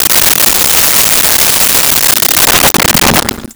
Sink Fill 08
Sink Fill 08.wav